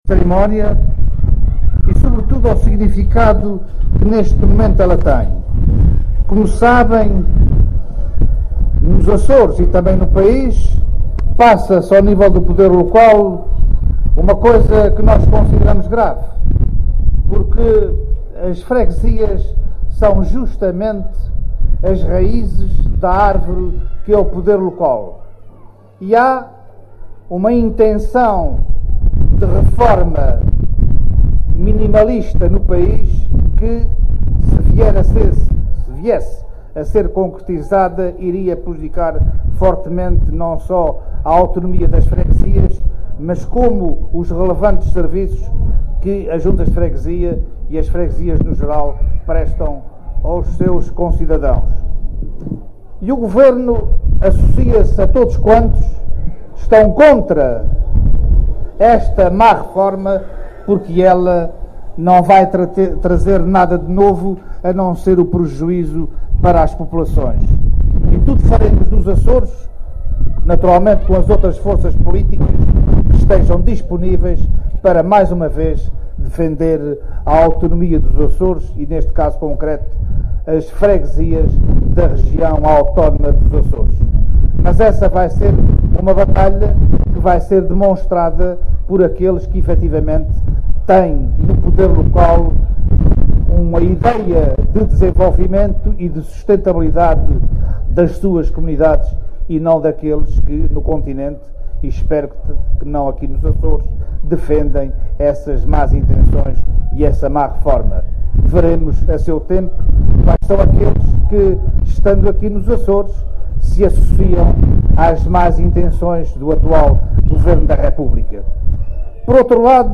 O Secretário Regional da Ciência, Tecnologia e Equipamentos, que presidiu à sessão solene do 10º aniversário de elevação da Ajuda da Bretanha a freguesia, elogiou a importância e a vitalidade do poder local, afirmando que apesar de os “tempos serem difíceis”, isso não pode representar a paralisação da atividade das juntas de freguesia, nesse sentido, “há sempre parceiros que têm noção das suas responsabilidades e garantem ajudas e apoios concretos”.